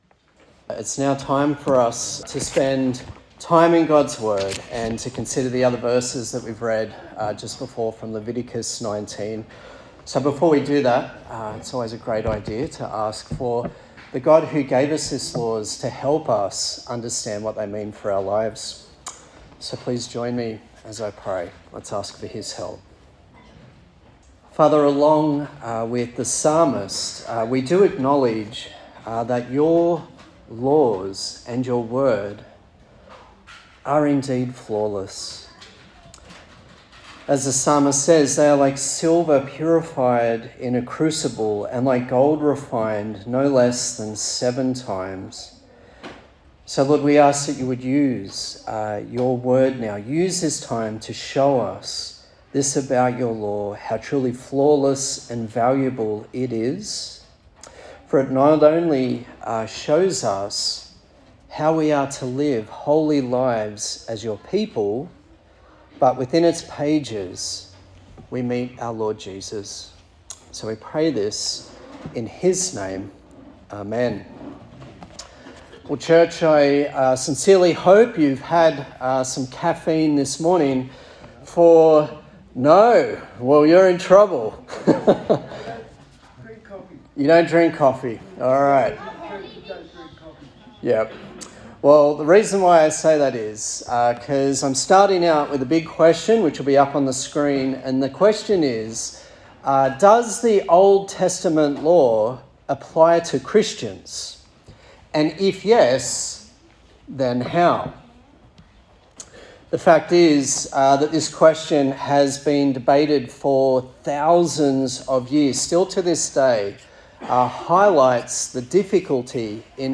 Leviticus Passage: Leviticus 19:19-37 Service Type: Sunday Service